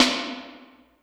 59 REV-SD1-L.wav